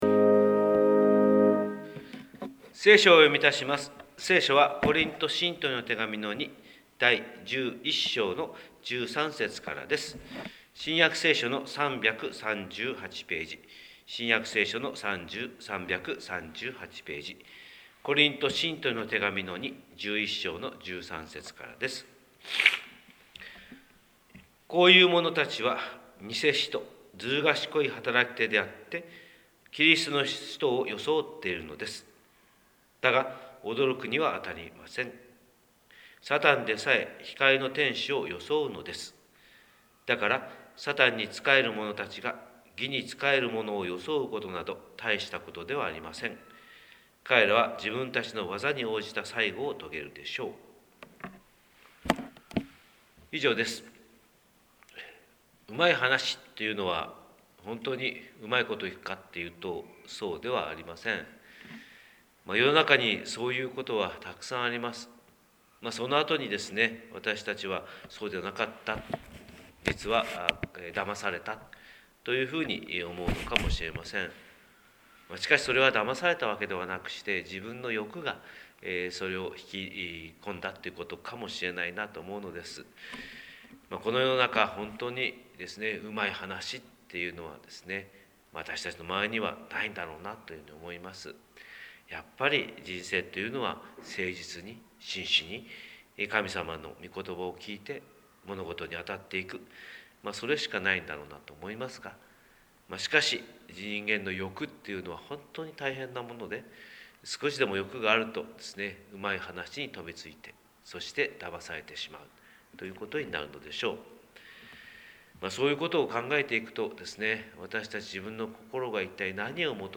神様の色鉛筆（音声説教）: 広島教会朝礼拝250306
広島教会朝礼拝250306「だまされないために」